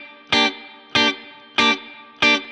DD_StratChop_95-Gmin.wav